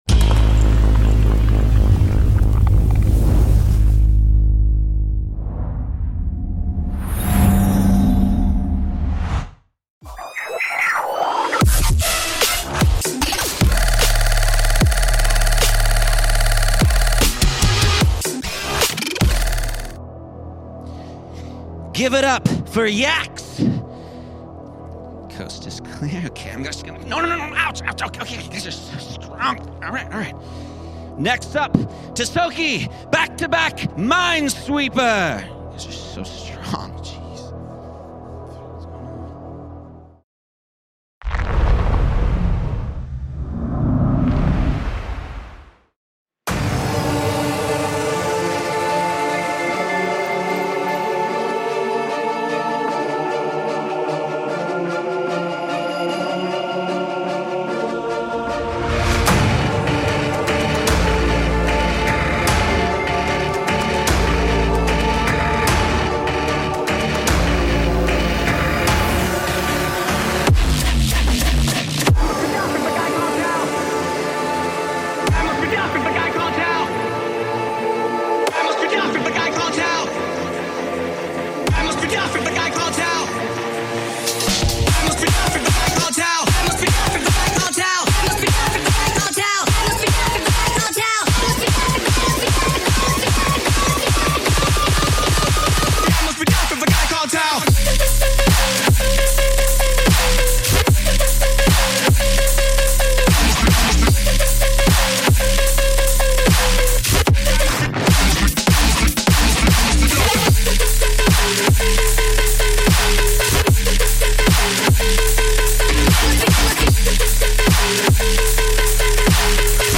Also find other EDM